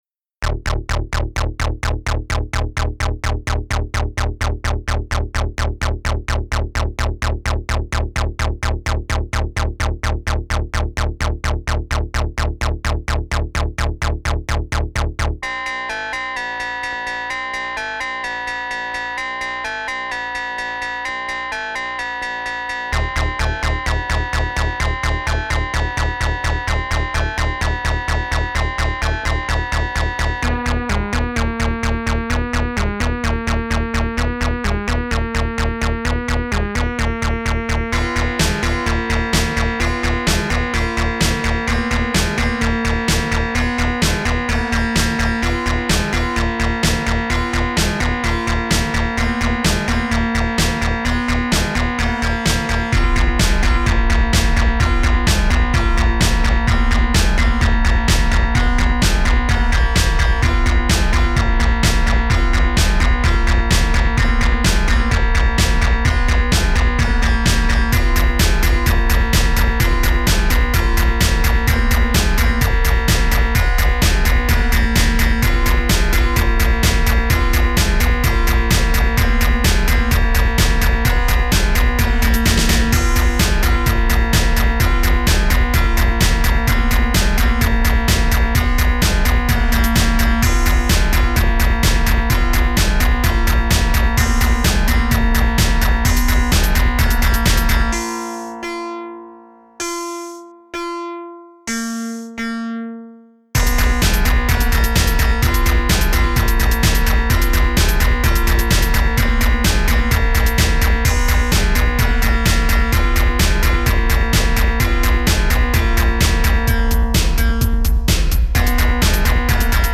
Music / House